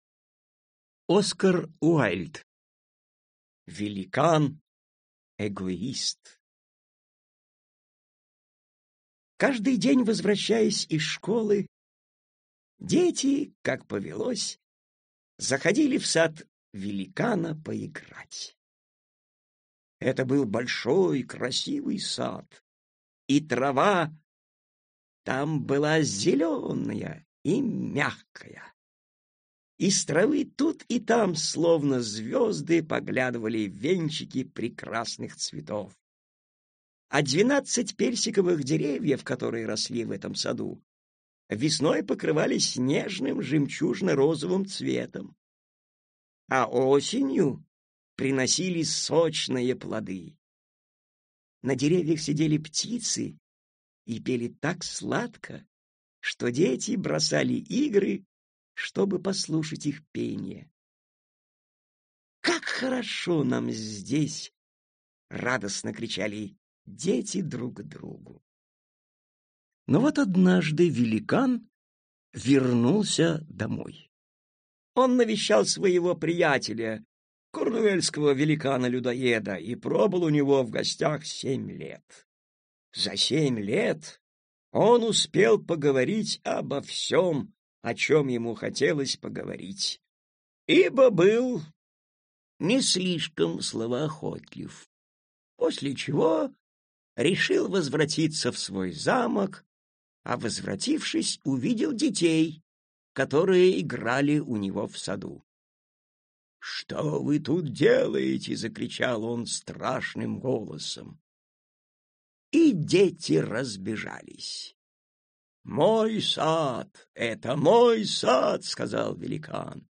Aудиокнига Сказки